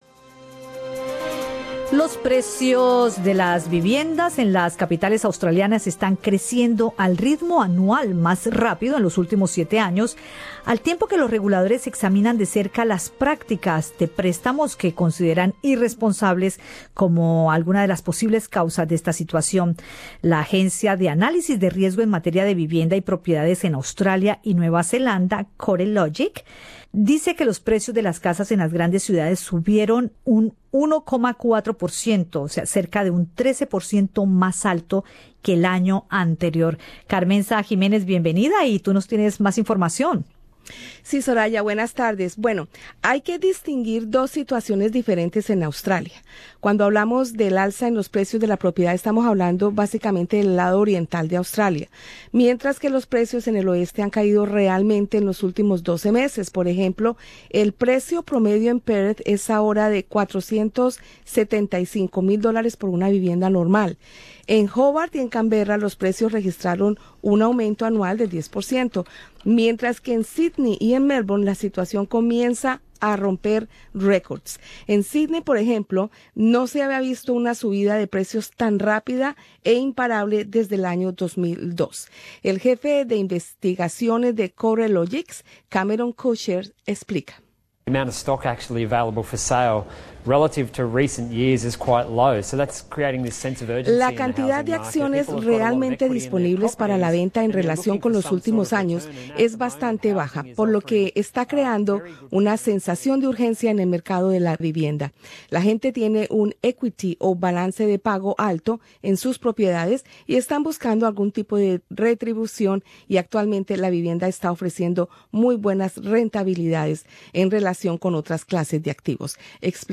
Los precios de las viviendas en las capitales australianas están creciendo al ritmo anual más rápido de los últimos siete años, al tiempo que los reguladores examinan de cerca las prácticas de préstamos considerados irresponsables, como posibles causas de esta situación. Entrevista